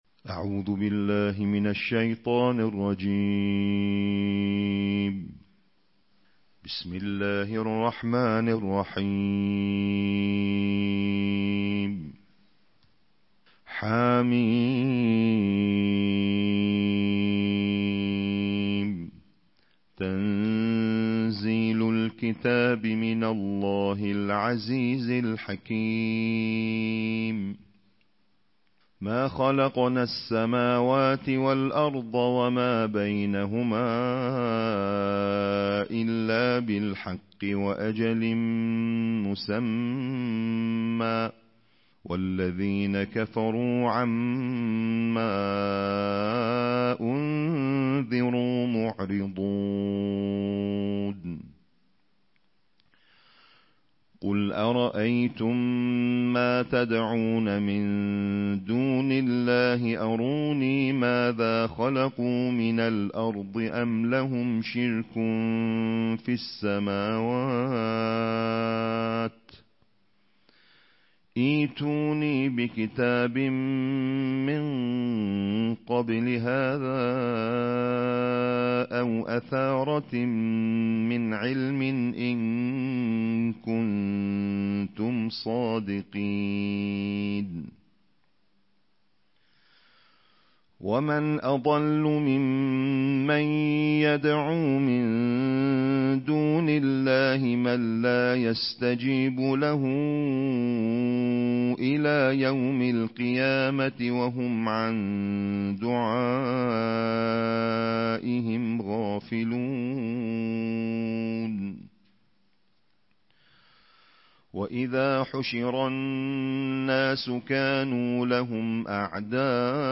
چھبیسویں پارے کی تلاوت بین الاقوامی قراء کی آواز میں+ آڈیو
رمضان المبارک کے چھبیسویں دن کی ترتیل تلاوت